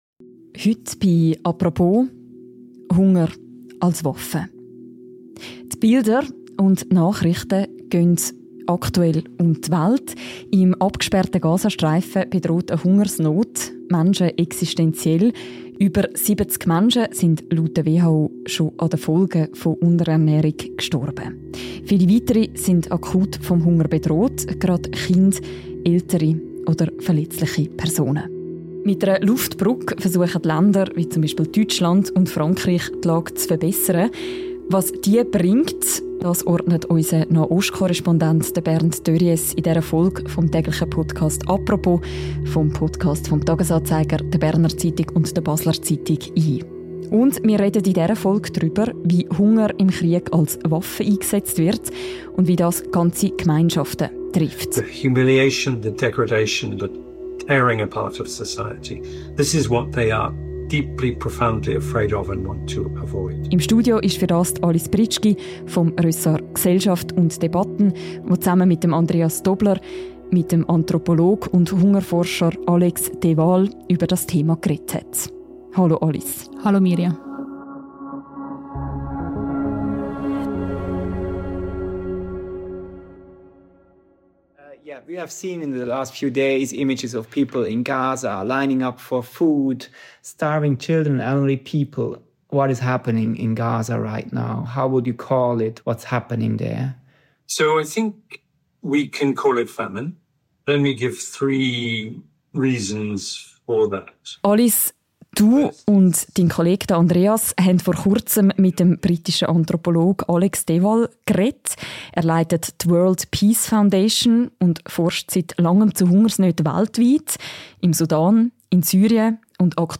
Zudem kommt in dieser Folge der britische Anthropologe und Hungerforscher Alex De Waal zu Wort.